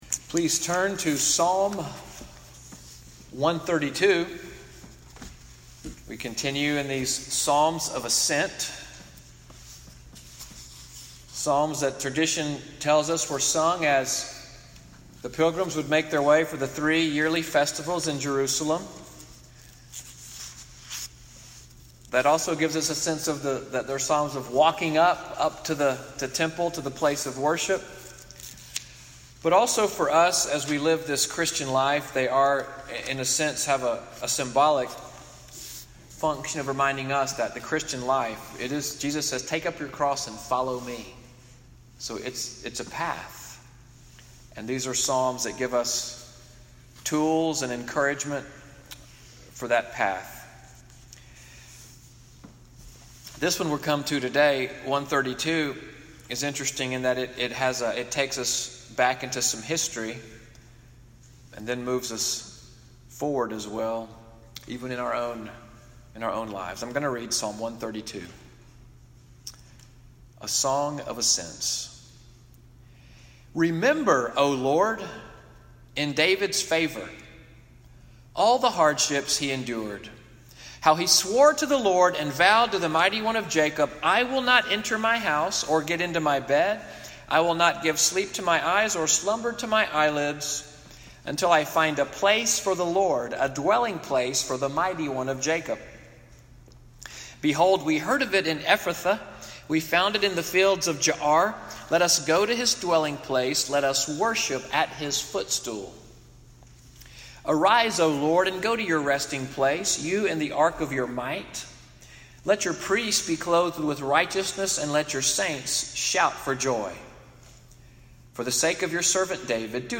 Sermon audio from MORNING WORSHIP NCPC-Selma, sermon, “Prayers & Promises” (28:14), preached August 12, 2018.